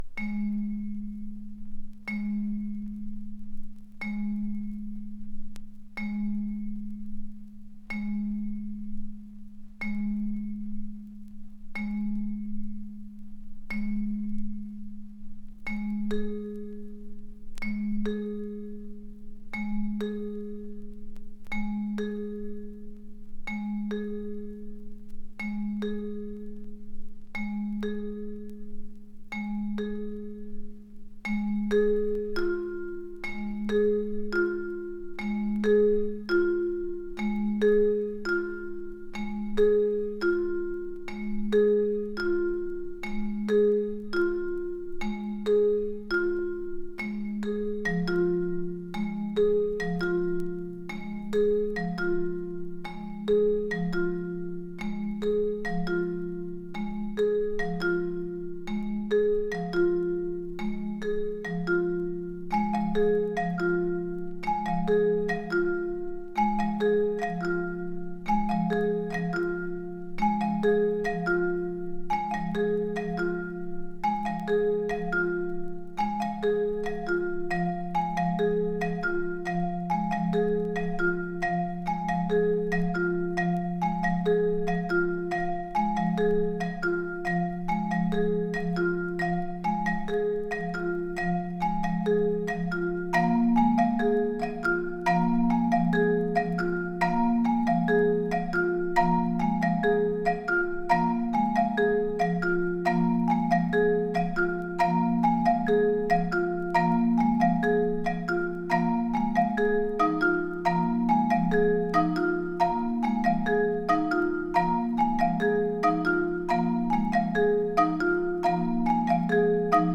【CONTEMPORARY】【ETHNIC】